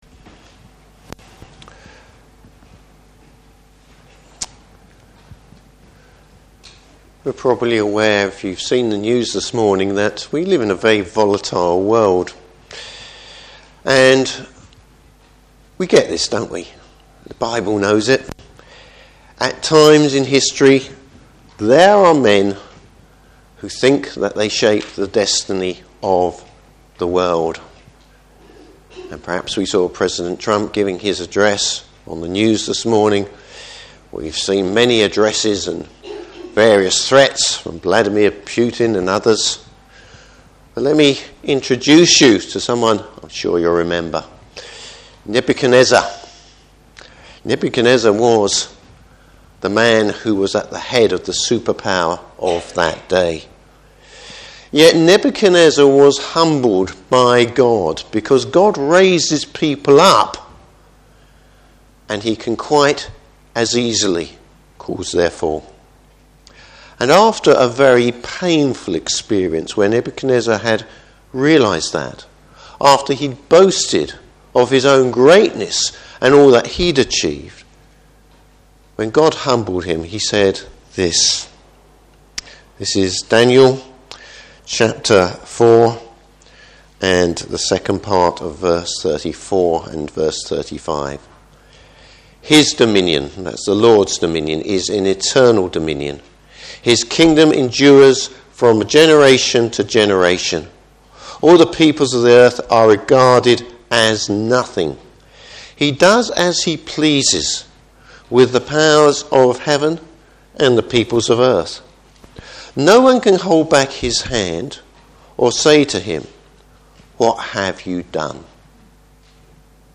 Service Type: Morning Service History belongs to the Lord. Topics: The sovereignty of God.